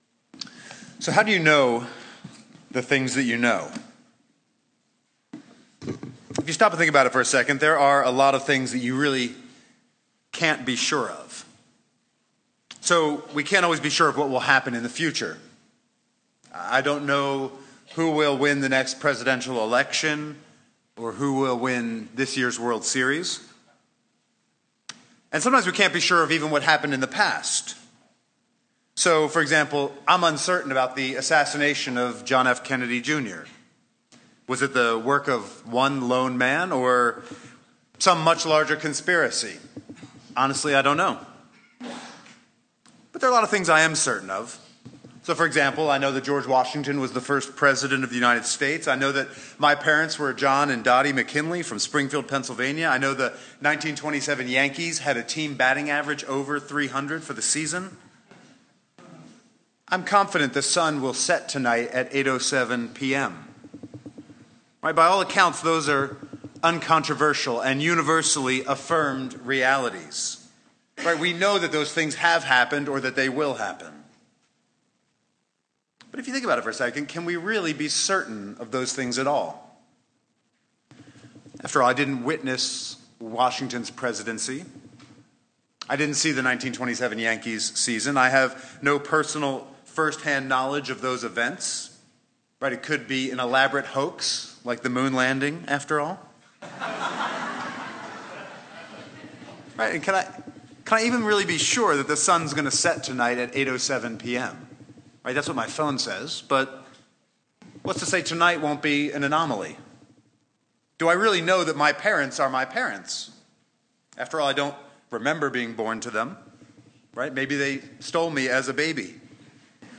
#5) He Includes A Little Consistent Humor: I say “consistent humor” in that the humor is germane and fitting to the subject —  not just humor for humor sake or unrelated to his other content [ i.e. — “It could be an elaborate hoax like the moon landing after all.”]
However, “Public Address” is a different art form and allows you to do with words what “writing” does not technically allow!